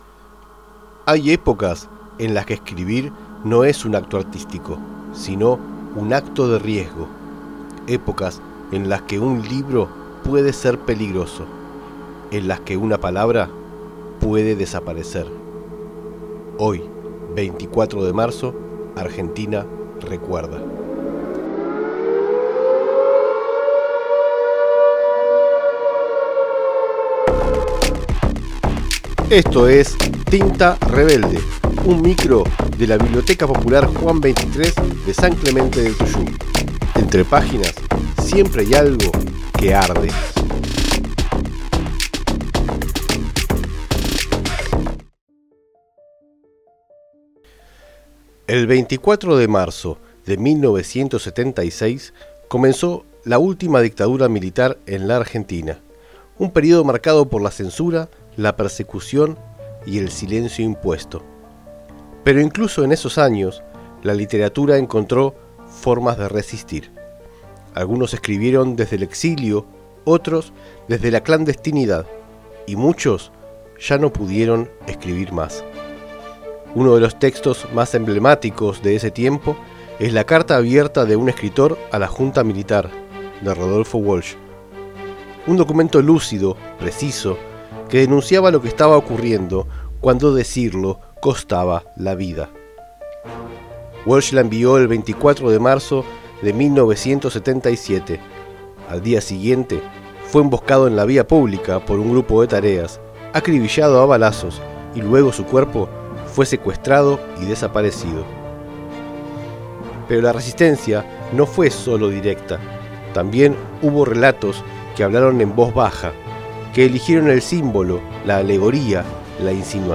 Un microprograma de la Biblioteca Juan XXIII de San Clemente del Tuyú